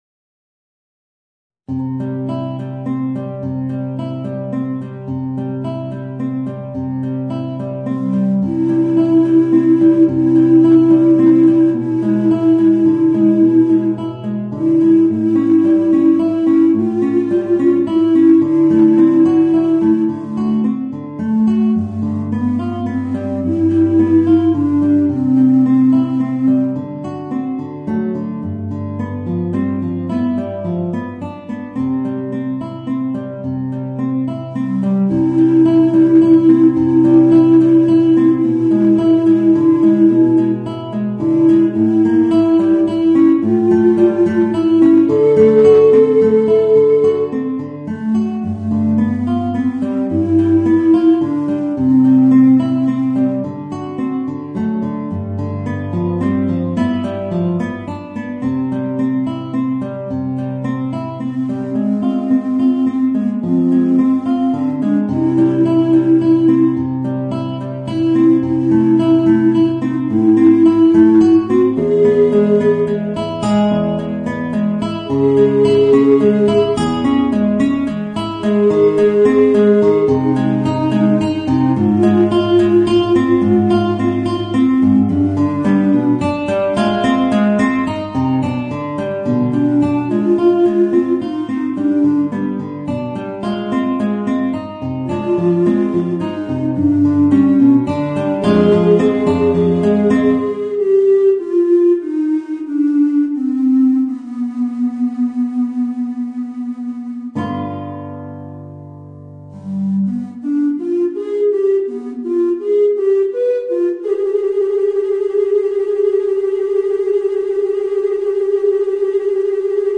Voicing: Guitar and Bass Recorder